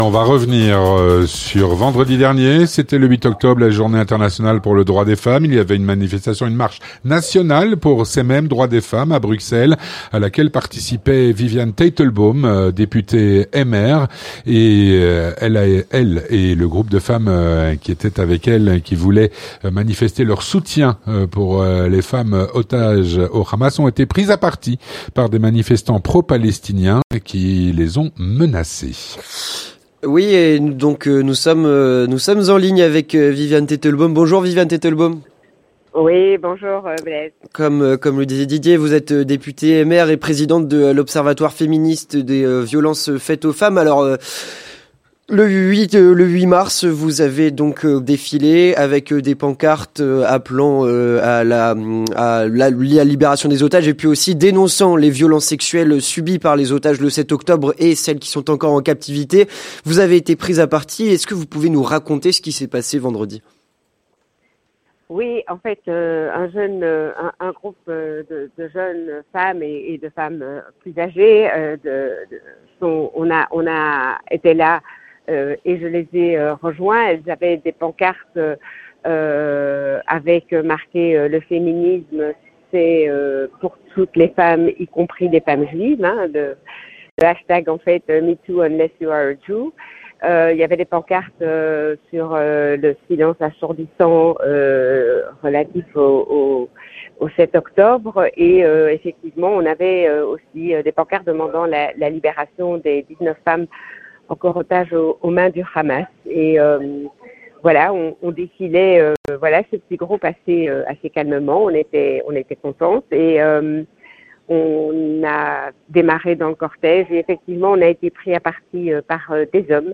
Avec Viviane Teitelbaum, Députée MR au parlement bruxellois, qui faisait partie des femmes menacées lors de cette marche.